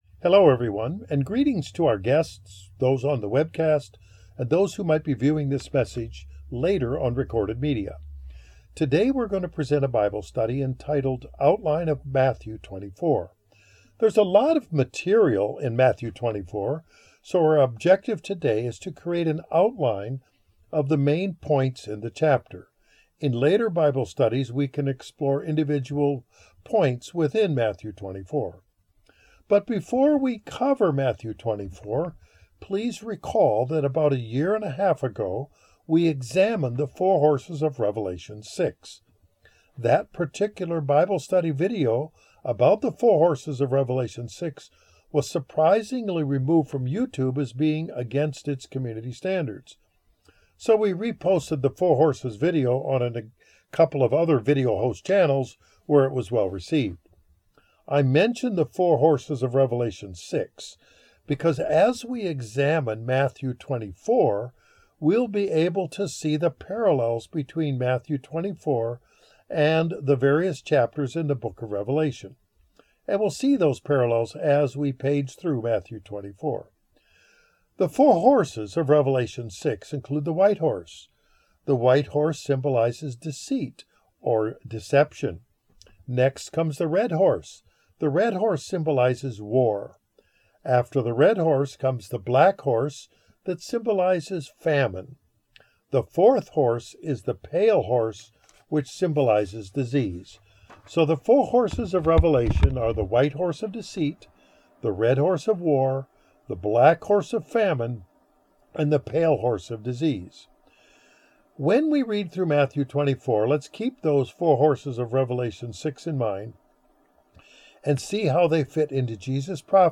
This Bible study provides an overview of the book of Matthew, Chapter 24, where Jesus Christ outlines prophetic fulfillments to come. We are encouraged to do further study on the subject in the book of Revelation.